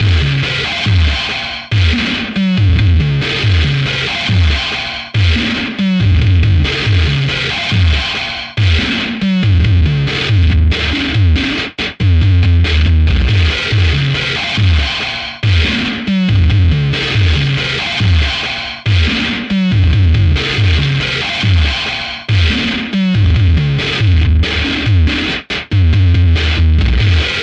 Attack loopz 2 " 75 bpm Acoustic New Metal Wall Attack loop 8
描述：ATTACK LOOPZ 02是一个使用Waldorf Attack drum VSTi制作的循环包，并在循环中应用了各种放大器模拟器（包括Cubase 5）的效果。我使用Acoustic kit来创建循环，并创建了8个不同顺序的循环，速度为75 BPM，长度为4/4的8个措施。
各种效果都是相当失真。
标签： 4 75bpm drumloop
声道立体声